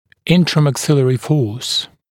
[ˌɪntrəmæk’sɪlərɪ fɔːs] [ˌинтрэмэк’силэри фо:с] сила, действующая в пределах одной зубной дуги